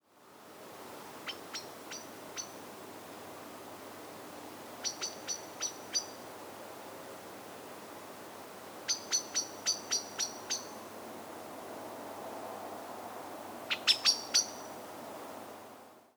Blackbird alarm call.